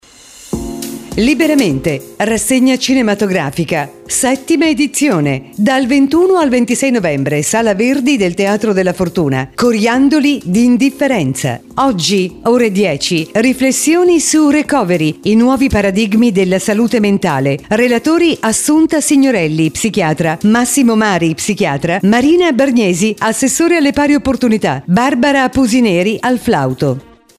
Spot radiofonici Rasscinema 2016